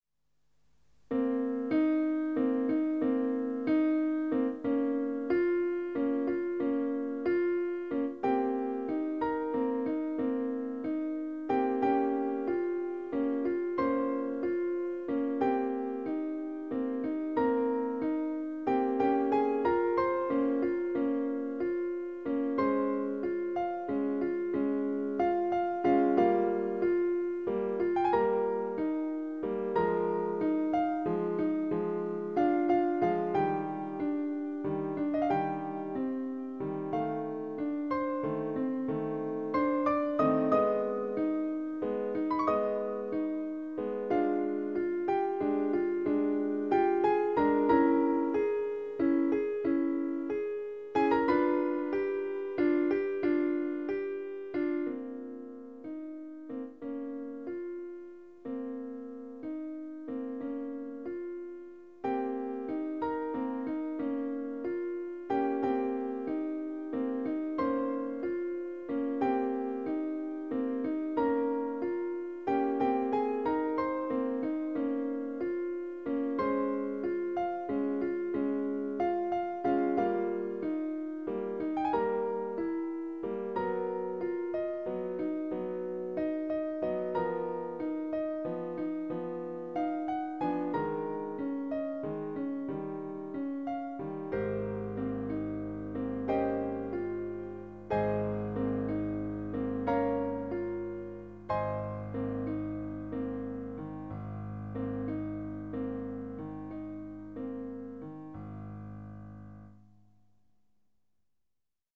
klavier solo